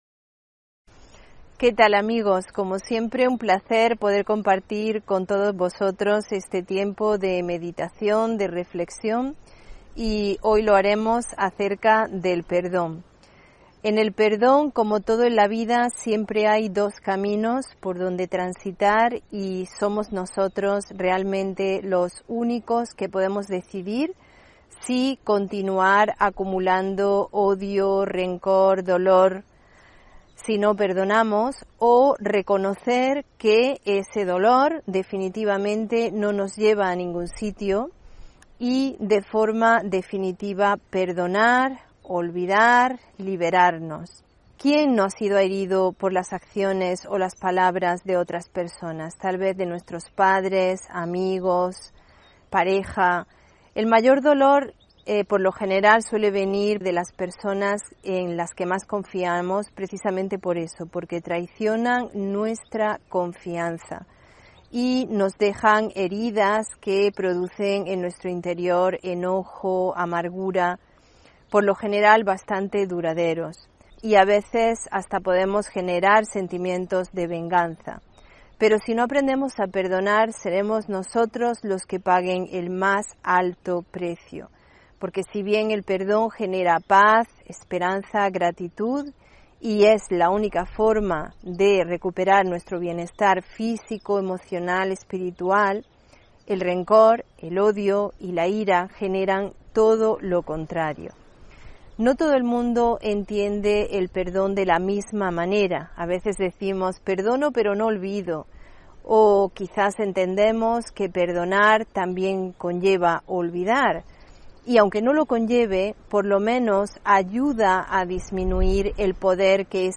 Meditación de la mañana: Atención natural